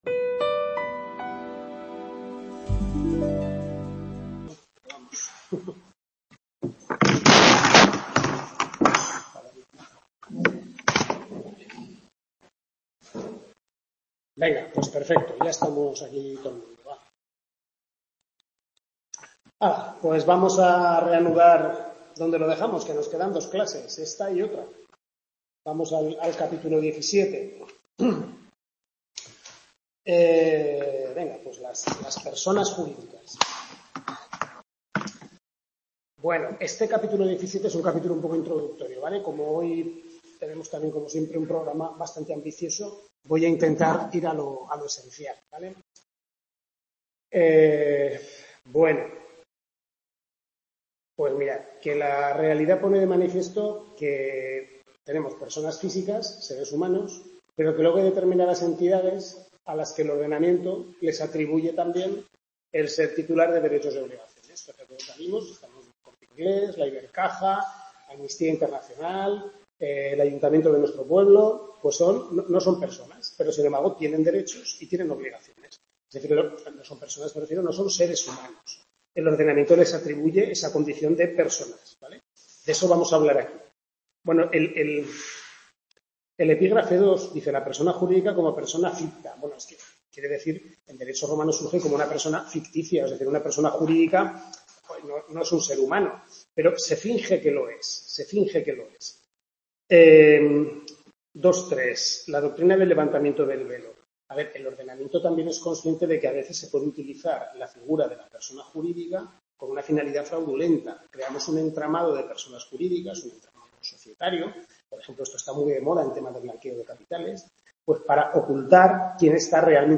Tutoría 5/6 Civil I (Parte General y Persona)